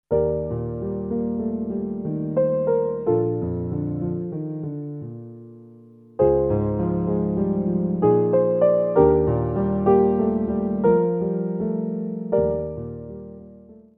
piano solos